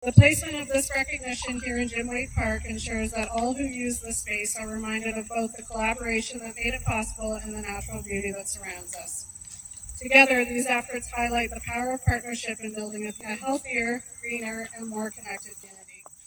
At a ceremony Tuesday morning, the Ontario Stone, Sand & Gravel Association (OSSGA) announced Lake Margaret is the recipient of their 2025 Bronze Plaque Award.